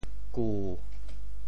旧（舊） 部首拼音 部首 臼 总笔划 17 部外笔划 11 普通话 jiù 潮州发音 潮州 gu7 潮阳 gu7 澄海 gu7 揭阳 gu7 饶平 gu7 汕头 gu7 中文解释 旧 <名> (形声。